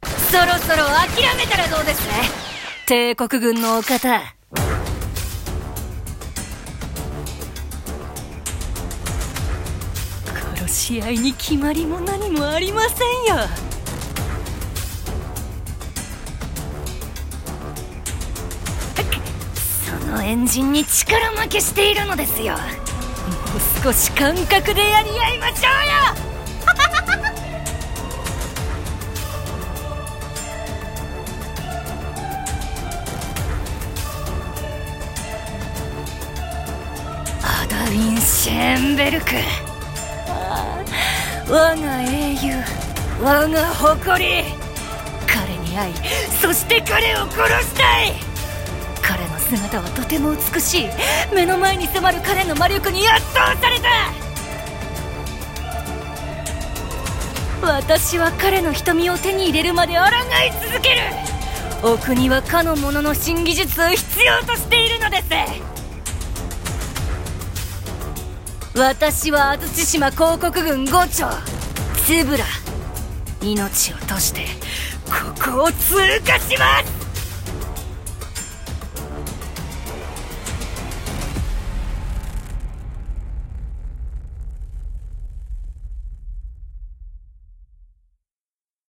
【声劇】